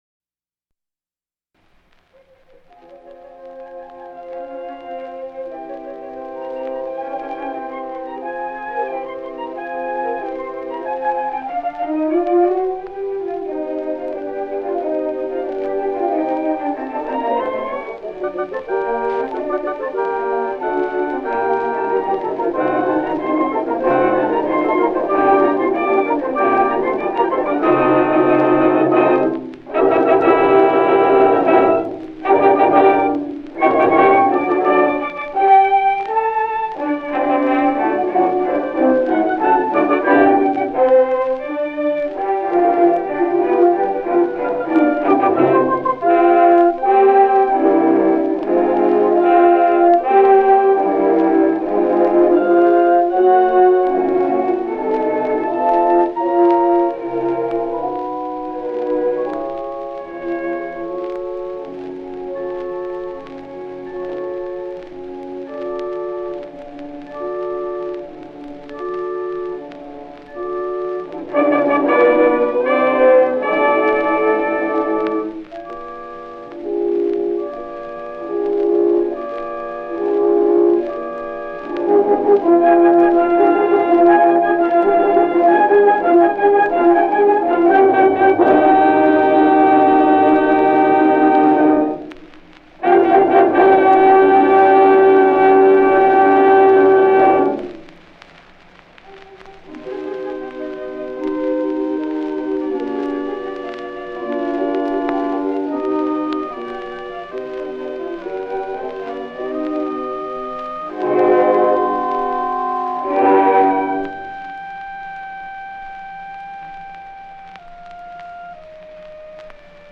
The great phonographic time machine has left us at least one major recording by Nikisch with his Berlin Philharmonic, a 1913 Beethoven Fifth not “live” but lively enough.
The first movement is a fascinating tissue of carefully organized and inevitable-sounding flexibility.
Moving now a little faster, Nikisch cradles nicely the sweet and rather interrogatory second theme, then the pot boils faster before the development is launched, guardedly at first then more animato.
Then, Beethoven’s recapitulation achieved, Nikisch eases elegantly into the oboe’s celebrated adagio cadenza, pacing it very slowly, clothing it with his solo oboe’s connivance in a more amorous aura than most of his colleagues past and present have dared conjure.